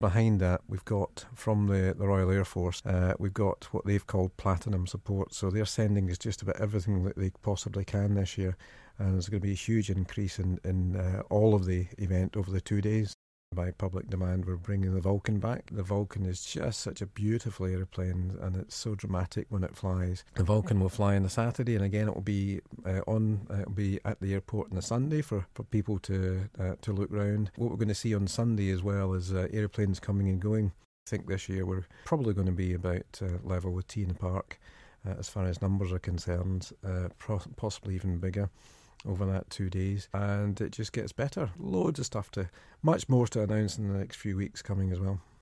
from the airshow on today's announcement.